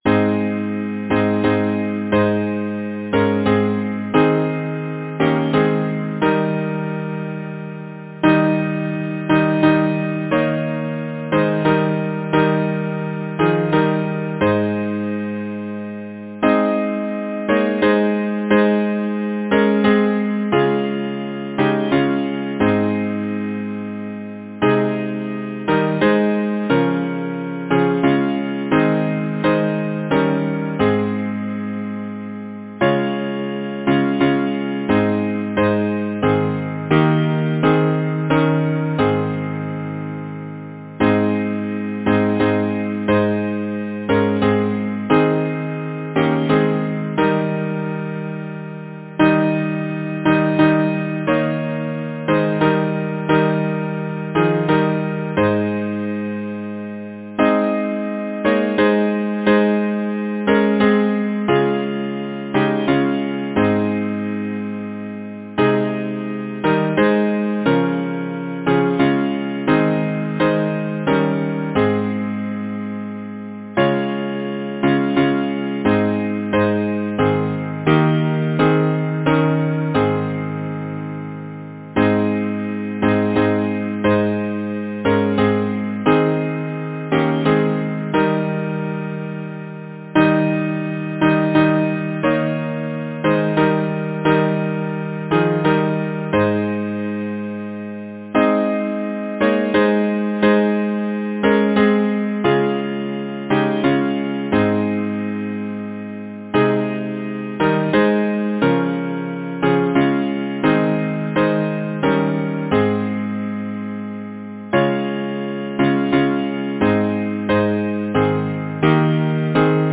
Title: The orphan’s prayer Composer: Alfred Arthur Graley Lyricist: Number of voices: 4vv Voicing: SATB Genre: Secular, Partsong
Language: English Instruments: A cappella